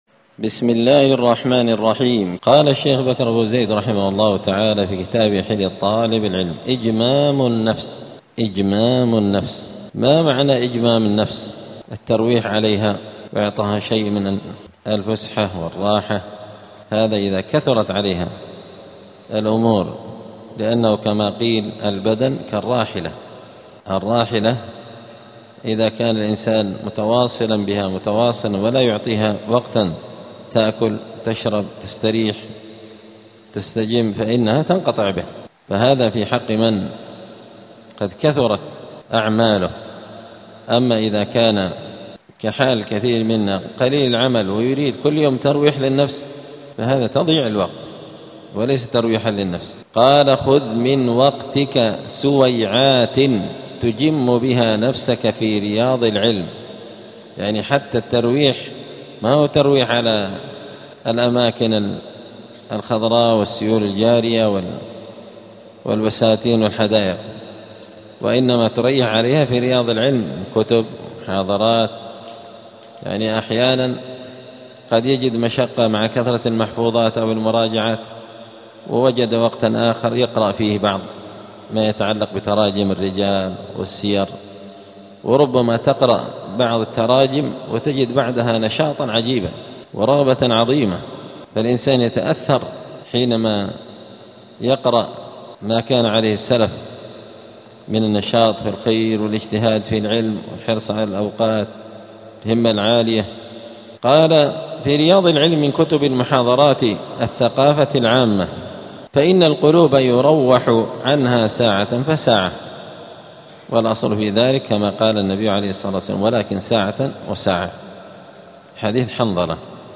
*الدرس التاسع والسبعون (79) فصل آداب الطالب في حياته العلمية {إجمام النفس}.*
دار الحديث السلفية بمسجد الفرقان قشن المهرة اليمن 📌الدروس اليومية